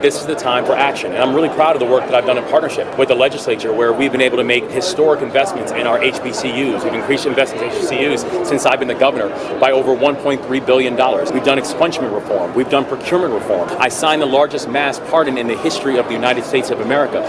Governor Wes Moore repeated his stand on the veto he handed down on setting up a Reparations Committee to look at redressing slavery in the state. The Governor told reporters now enough study has been done on the subject…